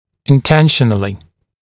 [ɪn’tenʃənəlɪ][ин’тэншэнэли]намеренно